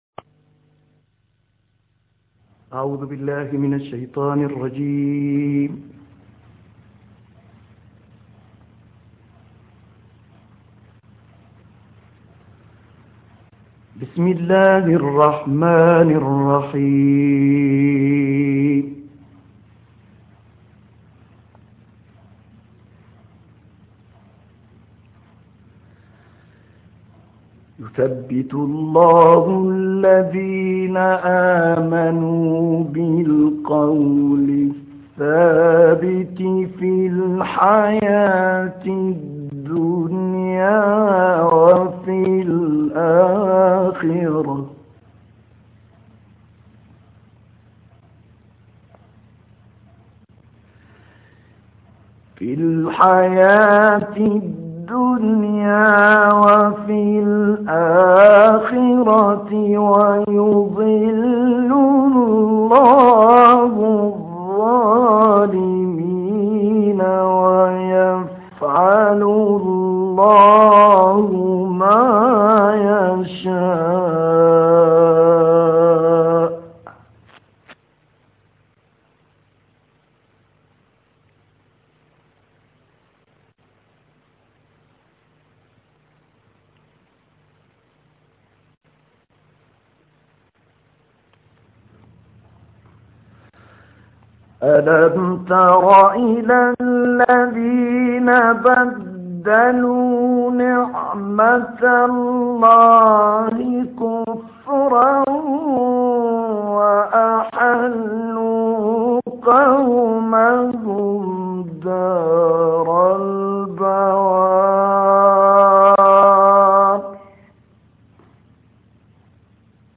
دانلود قرائت سوره ابراهیم آیات 27 تا 52 - استاد طه الفشنی
قرائت-سوره-ابراهیم-آیات-27-تا-52-استاد-طه-الفشنی.mp3